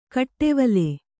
♪ kaṭṭevale